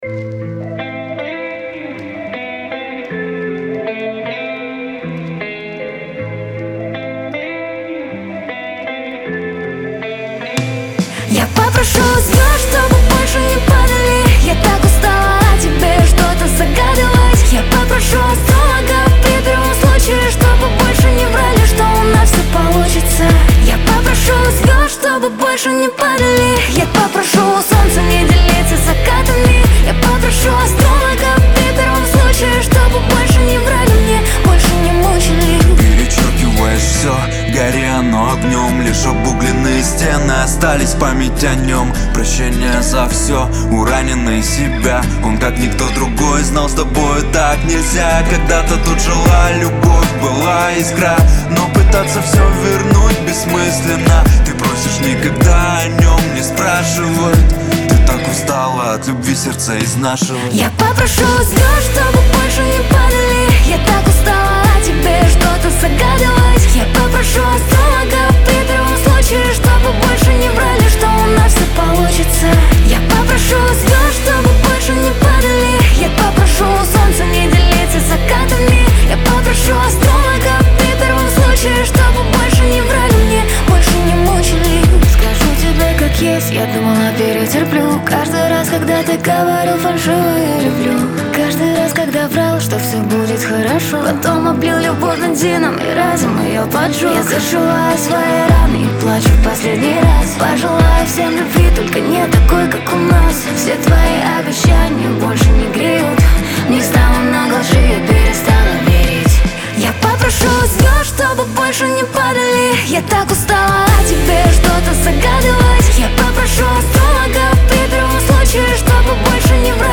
pop , дуэт
диско
эстрада